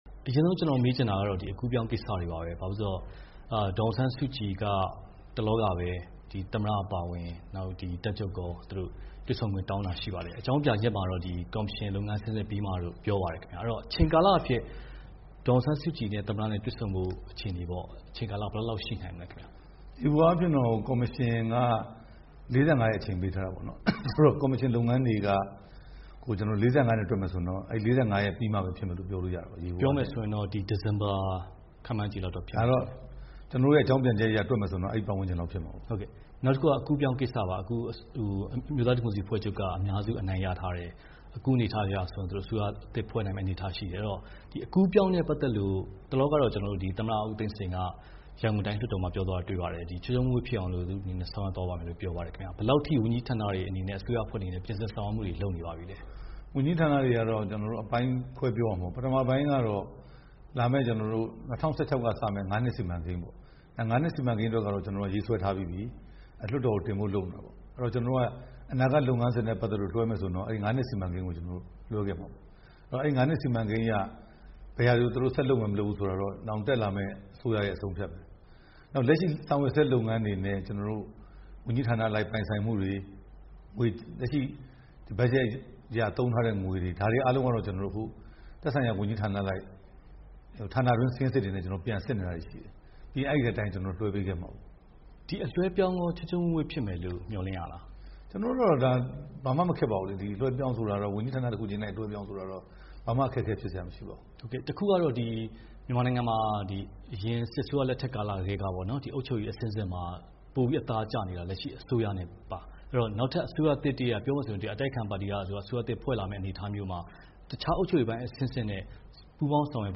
ပြန်ကြားရေးဝန်ကြီး ဦးရဲထွဋ်နဲ့ ဗွီအိုအေ တွေ့ဆုံမေးမြန်းချက်